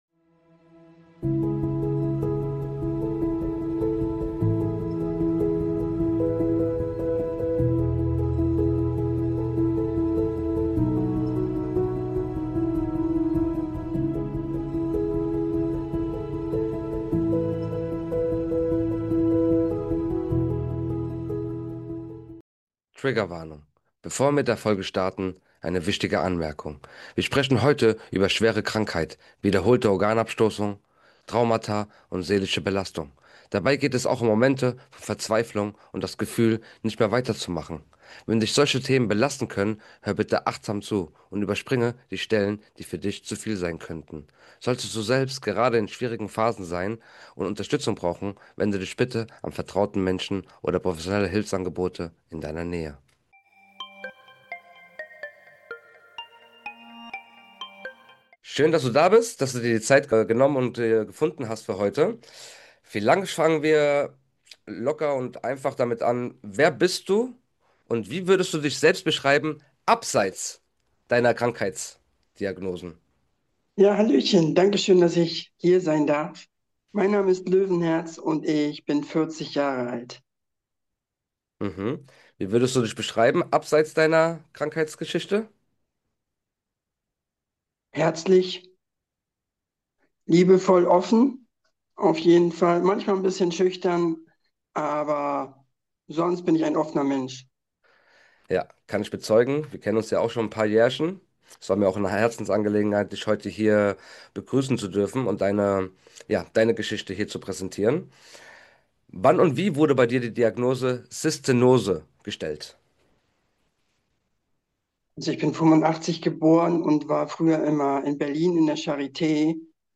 🎧 Ein Gespräch, das unter die Haut geht – über den Willen, weiterzuleben, wenn alles dagegen spricht.